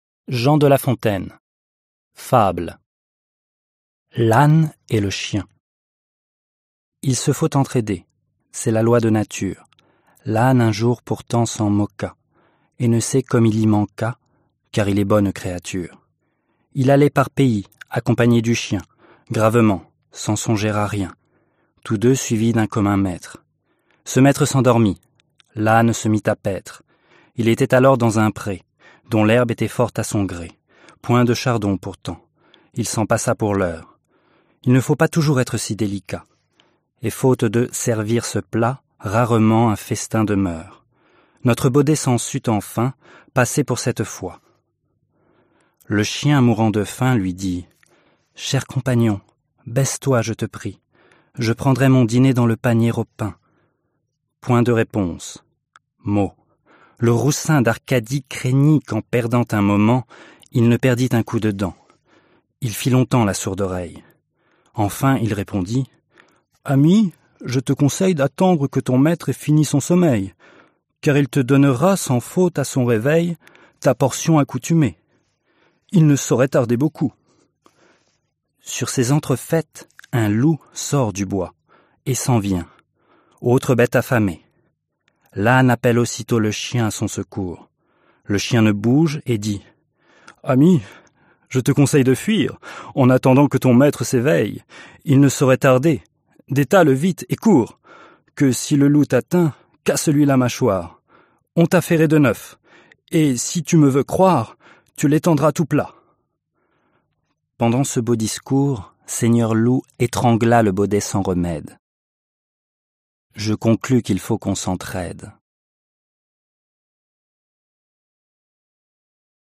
Аудиокнига Басни. Книга для чтения на французском языке | Библиотека аудиокниг